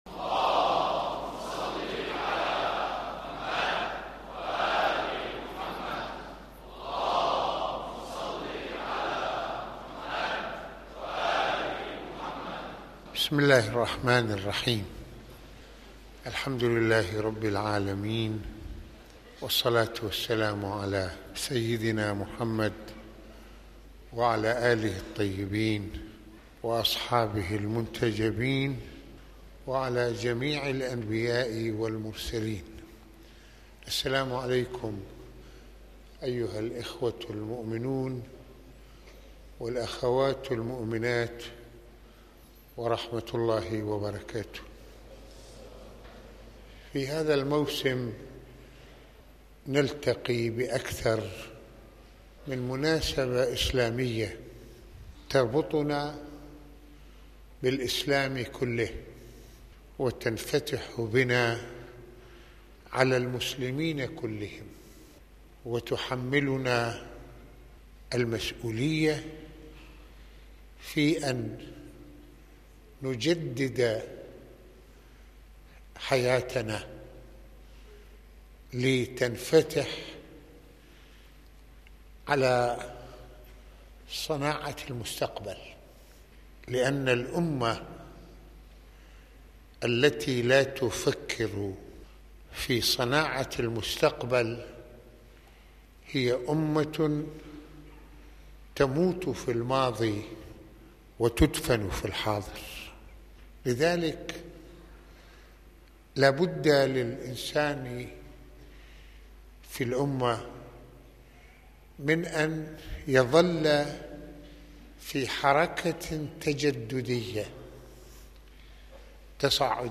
المناسبة : عاشوراء المكان : مسجد الإمامين الحسنين (ع)